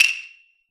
PERC - CUTBACK.wav